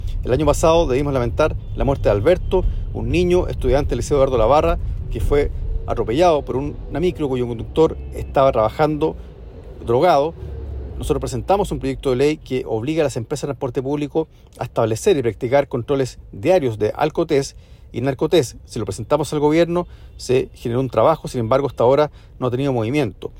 Junto a lo anterior, el parlamentario por la región de Valparaíso, recordó la fatal situación del estudiante y mencionó el contenido de la iniciativa;